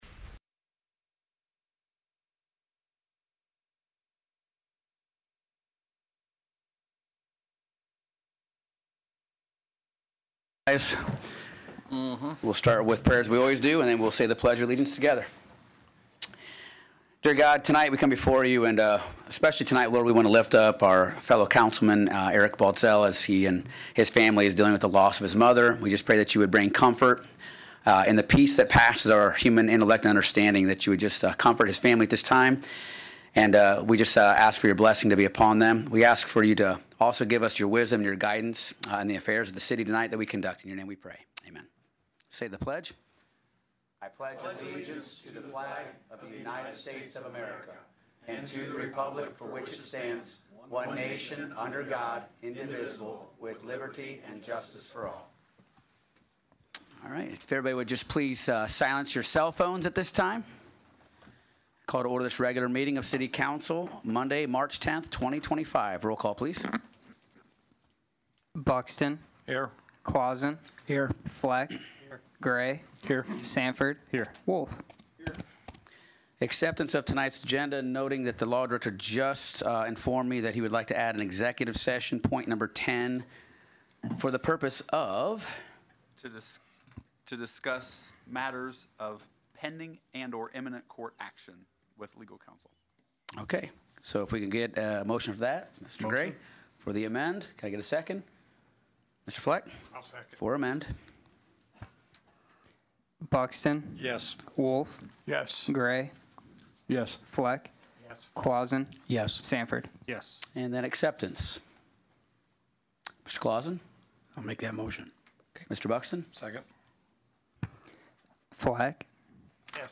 Celina City Council Meeting for March 10th
CELINA COUNCIL 3-10-25.mp3